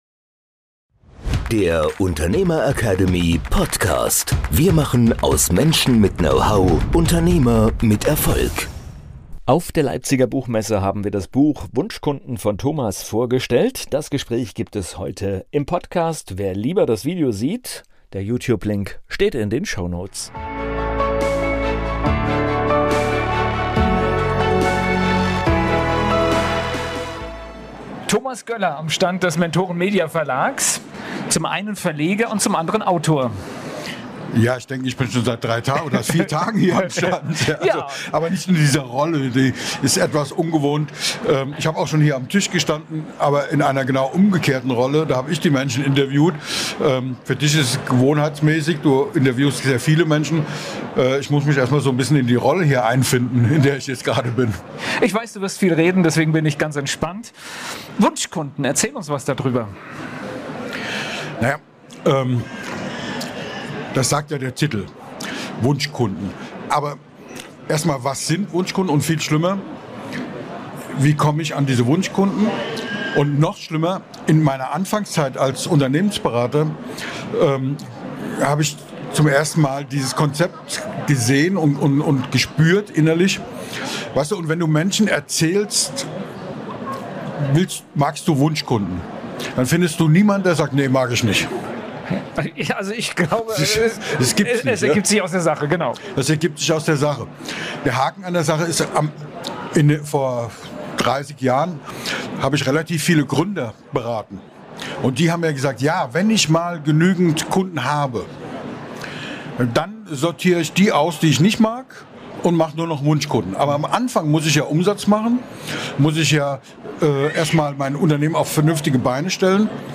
In der heutigen Folge senden wir den Live-Mitschnitt von der Leipziger Buchmesse 2026.